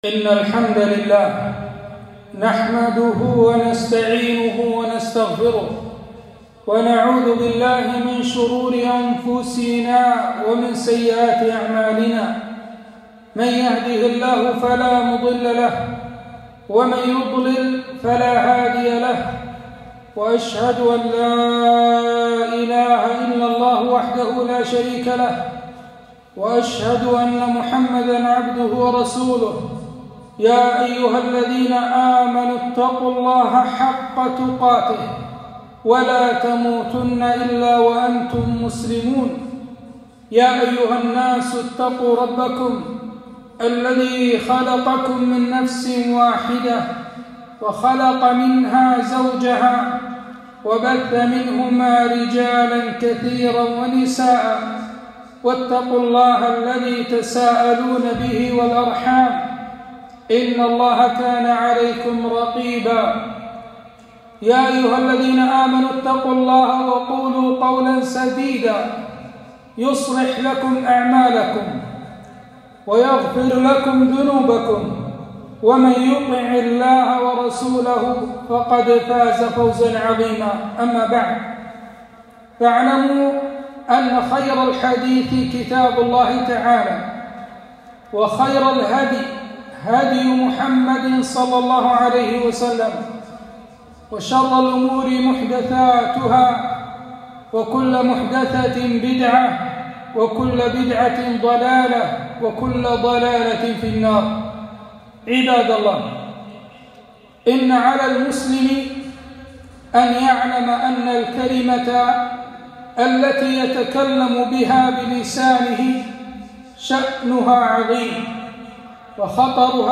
خطبة - أمسك عليك لسانك لايهلكنك